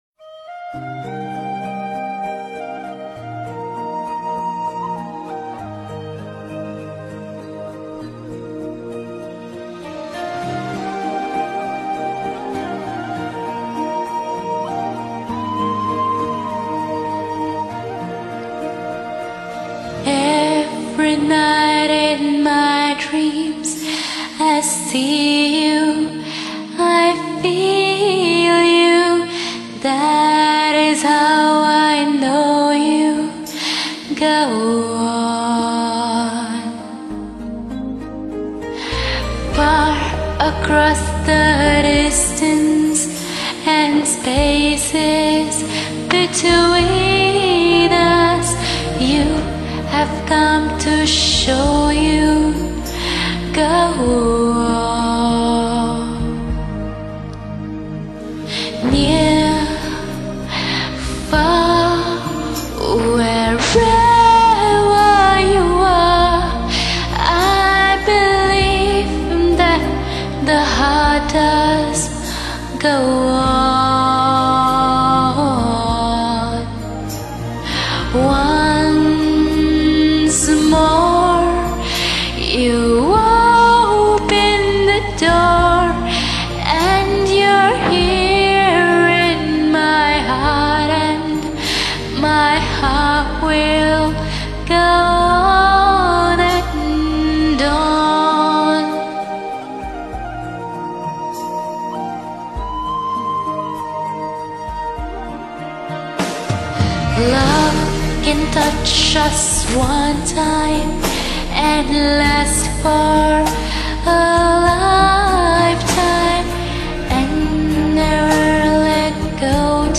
Nice Voice !!